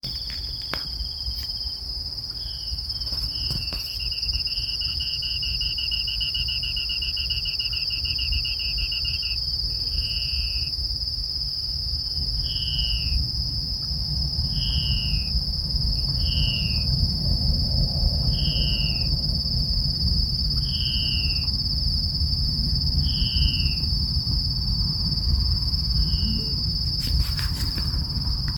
Ocellated Crake (Rufirallus schomburgkii)
Detailed location: Camino al Teyú Cuaré
Condition: Wild
Certainty: Recorded vocal